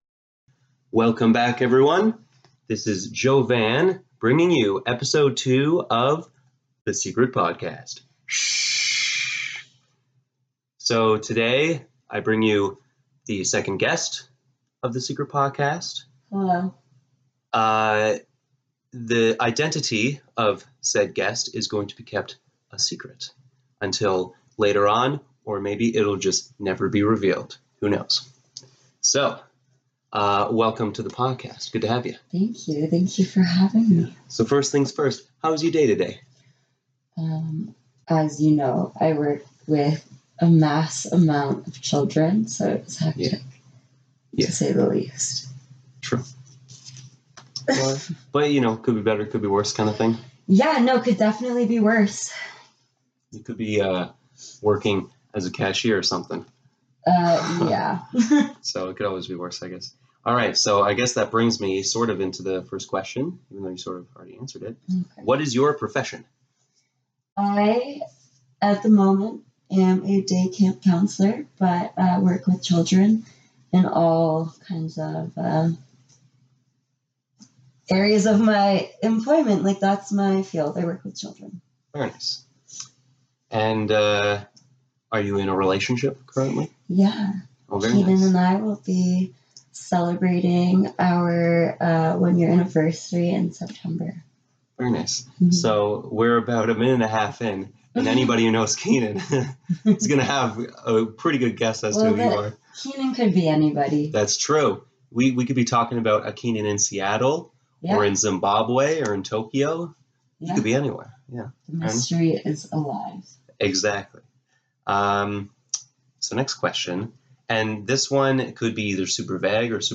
Here I interview a lady I've know for almost my whole life.